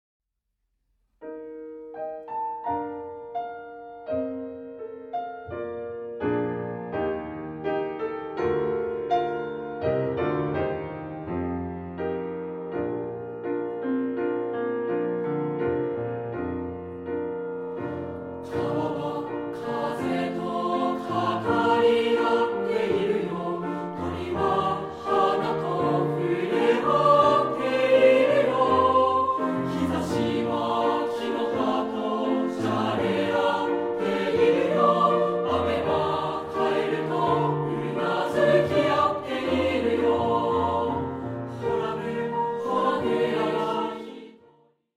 混声3部合唱／伴奏：ピアノ